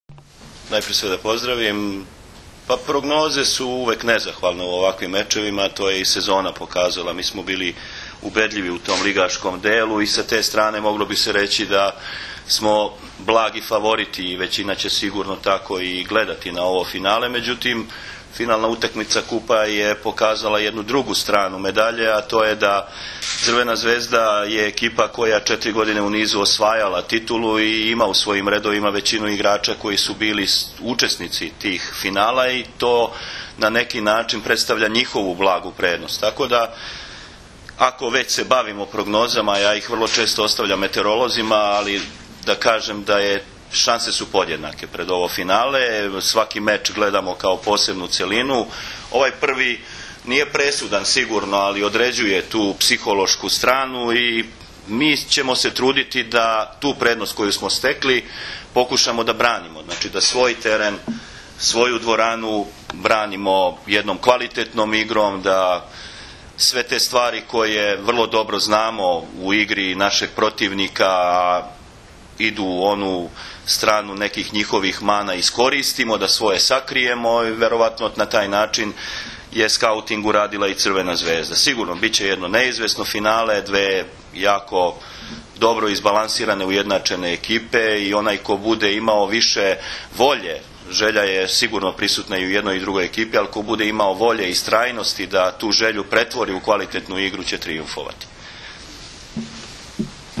Tim povodom, danas je u prostorijama Odbojkaškog saveza Srbije održana konferencija za novinare